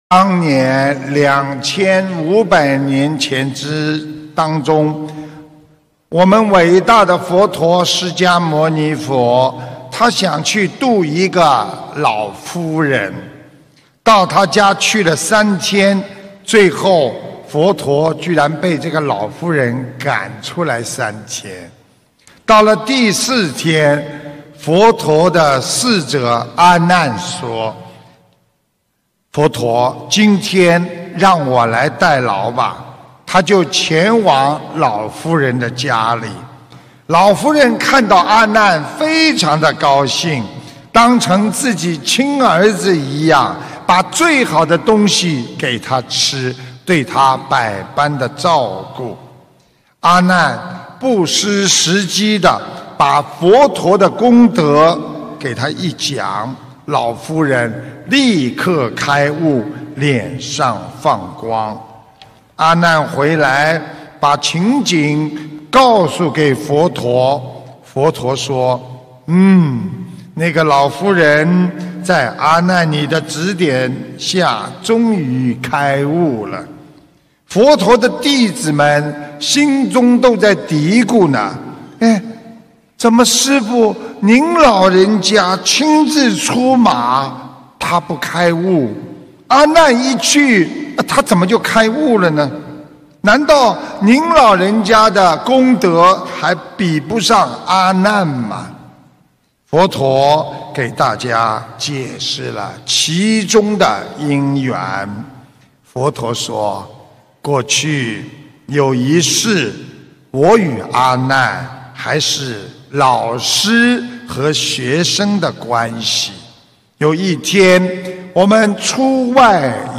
音频：佛陀告诉你为什么你总是与人家不投缘？·师父讲小故事大道理